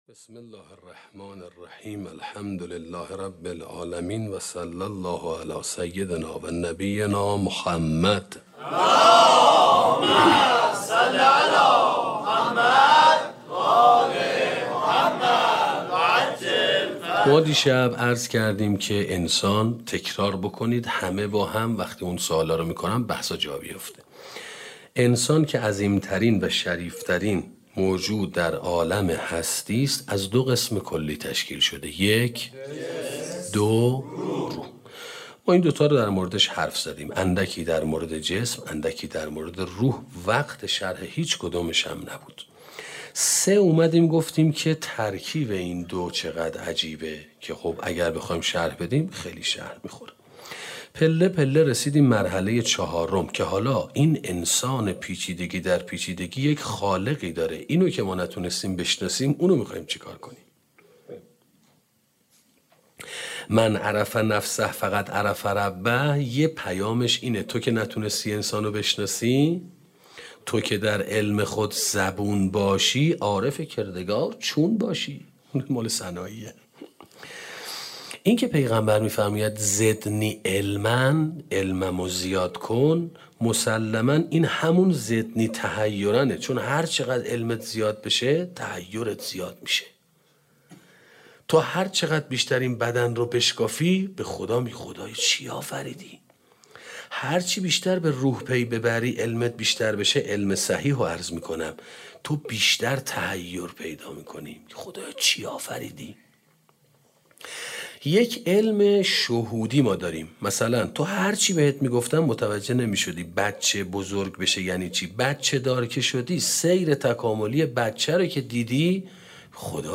سخنرانی سرگذشت انسان (عالم عهد) 2 - موسسه مودت